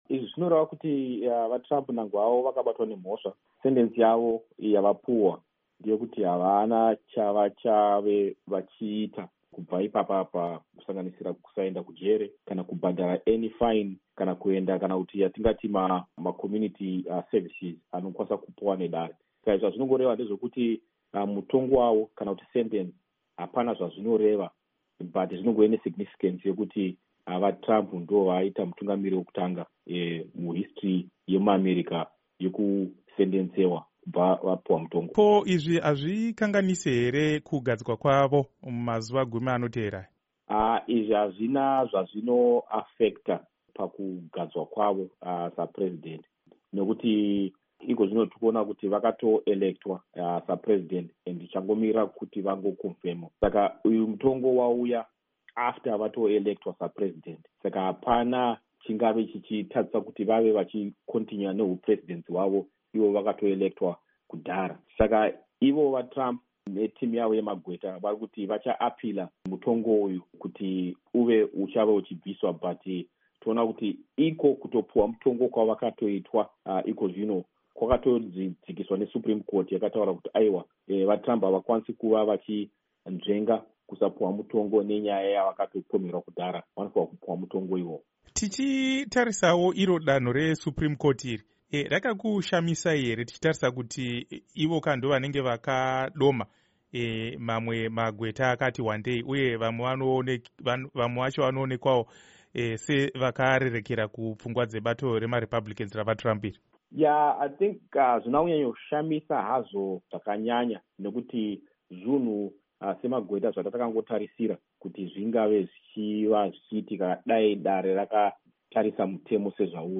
Gweta rinorwira kodzero dzevanhu
Hurukuro